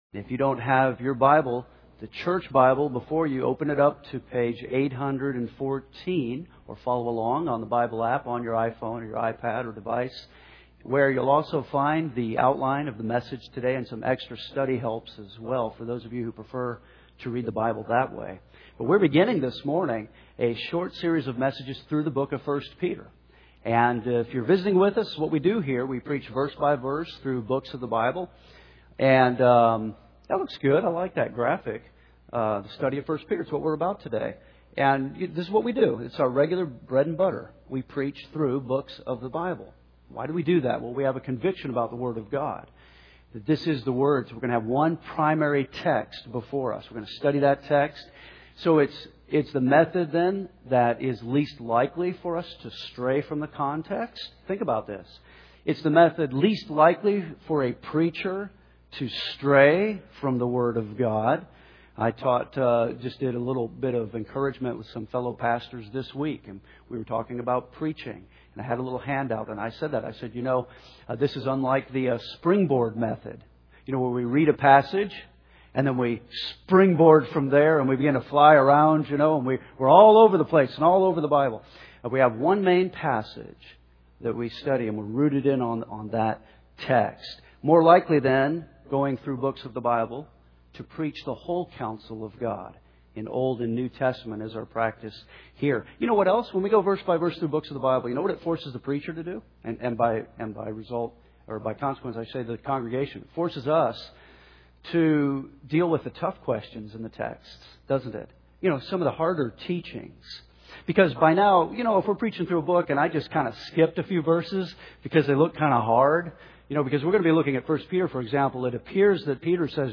This kind of preaching, expository preaching, is the least likely method to result in a preacher straying from God’s Word because we always have one main text, one main passage open before us.